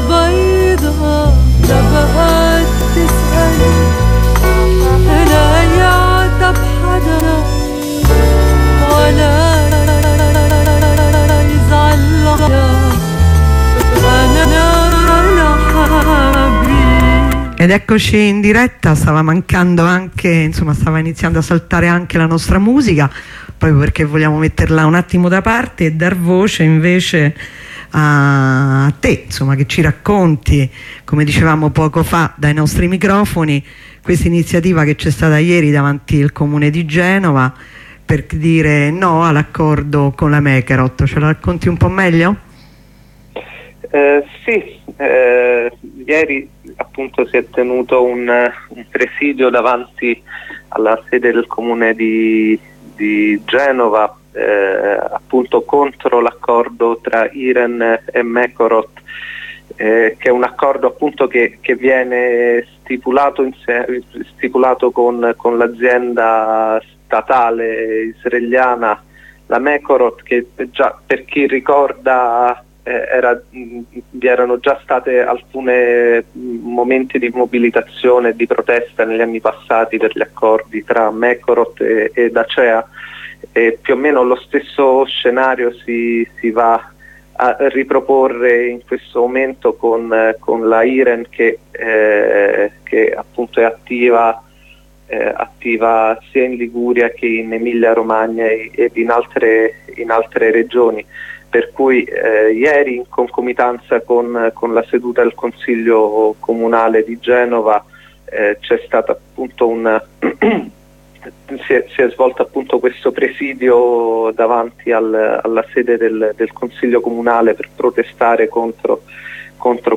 Ne parliamo con un ricercatore